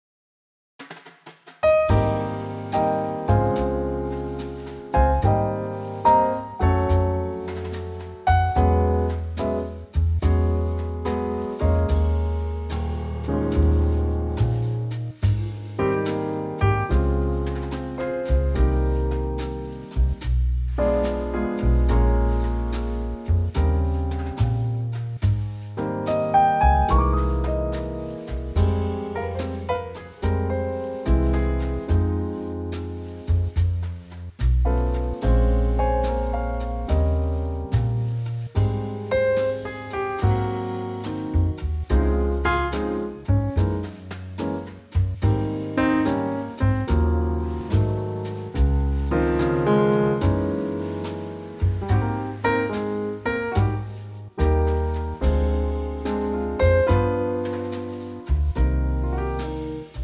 key - Ab - vocal guide - Ab to C
Beautiful Trio arrangement
32 bars of musical perfection.